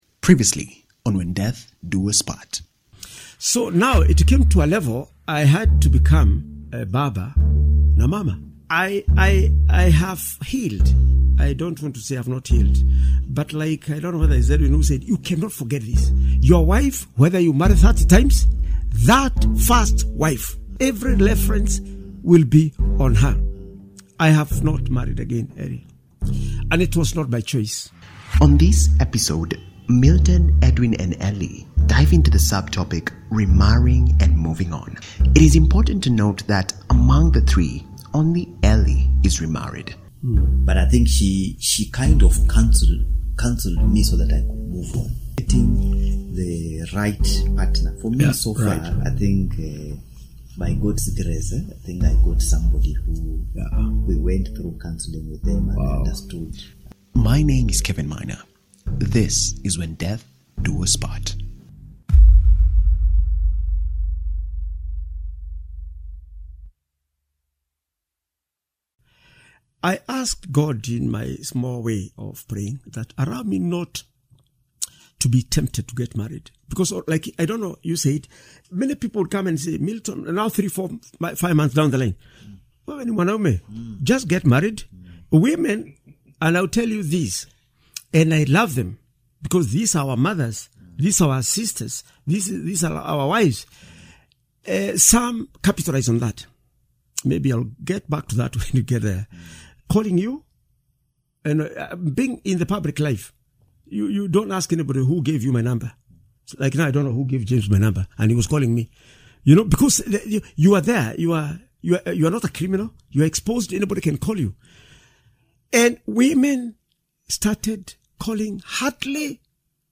In this podcast, when death do us part, three widowers, speak openly about the process of grief, how they found themselves exposed to the emotional turmoil of losing their wives, and how society does not understand on how to deal with men who have lost their wives. This open conversation touches on how to take care of children, dating and marrying again, and processes of grief in a humane and reflective mood.